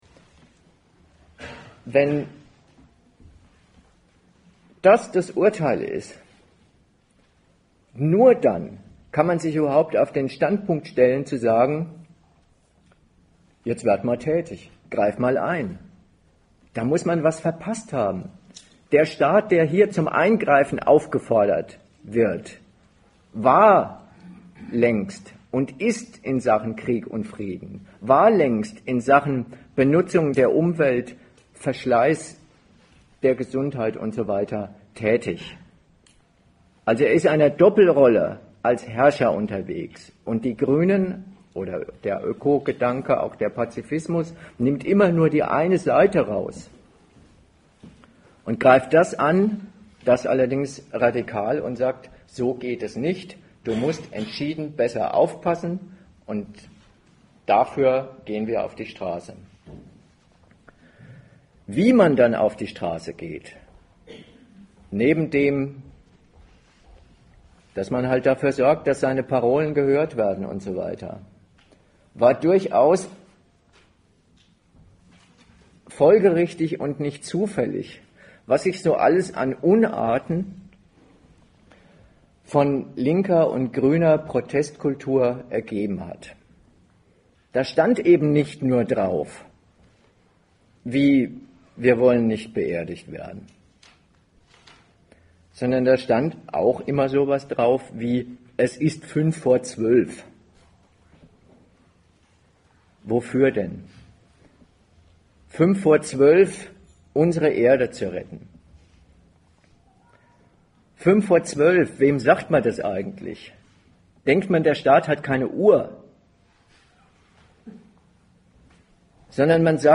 Datum 01.09.2011 Ort Berlin Themenbereich Rechtsstaat und Demokratie Dozent Gastreferenten der Zeitschrift GegenStandpunkt 30 Jahre nach ihrer Gründung sind die Grünen da, wo sie hin wollten: an den Schalthebeln der Macht.